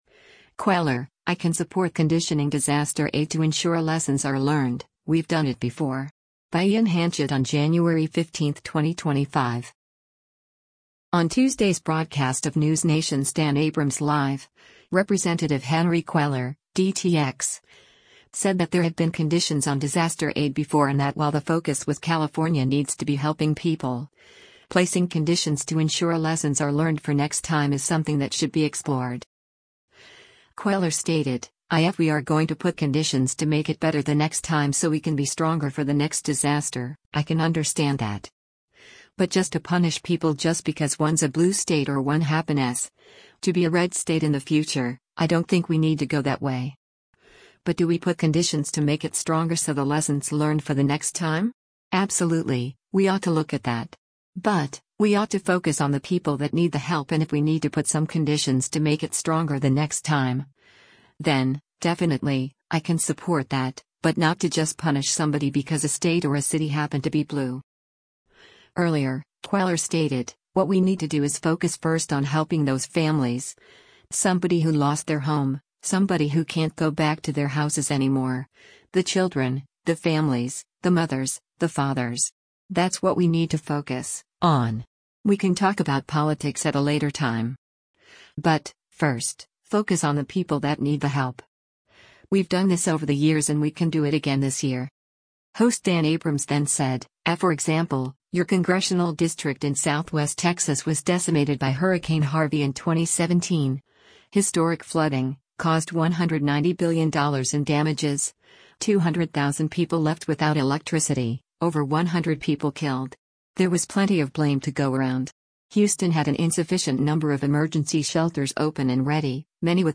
On Tuesday’s broadcast of NewsNation’s “Dan Abrams Live,” Rep. Henry Cuellar (D-TX) said that there have been conditions on disaster aid before and that while the focus with California needs to be helping people, placing conditions to ensure lessons are learned for next time is something that should be explored.